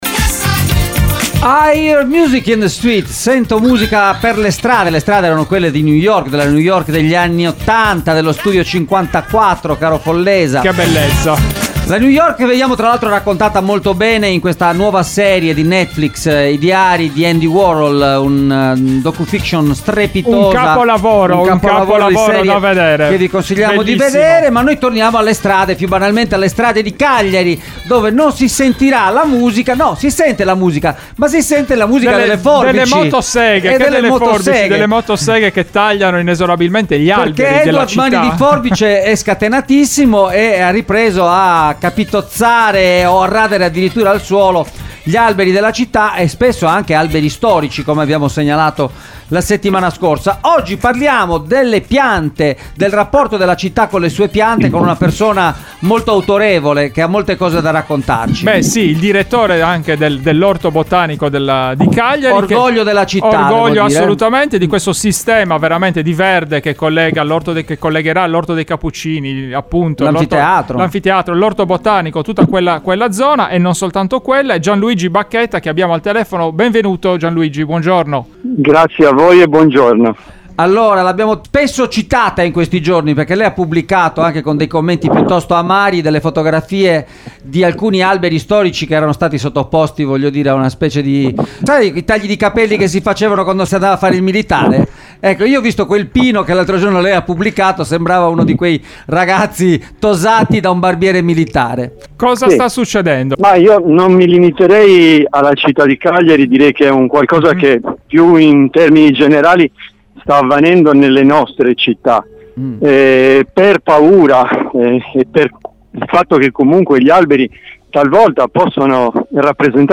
è intervenuto questa mattina ai microfoni di Extralive